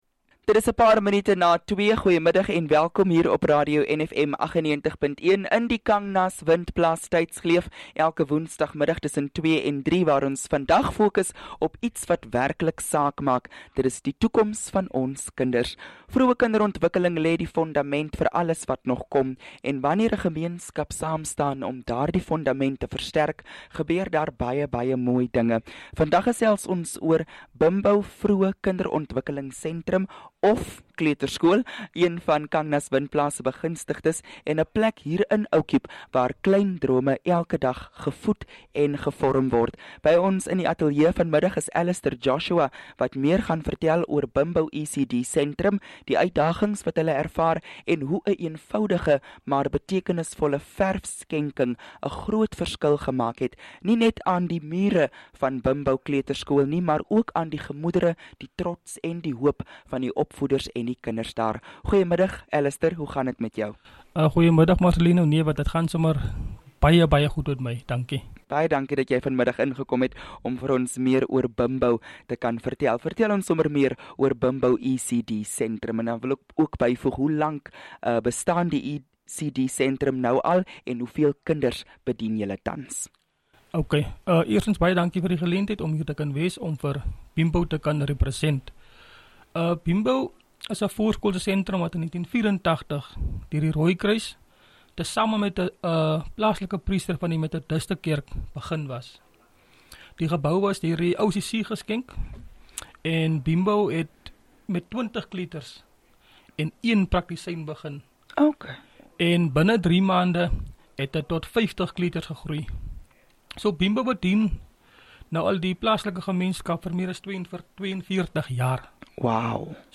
Attachments KANGNAS INTERVIEW 21 JAN 2026.mp3.mpeg (7 MB)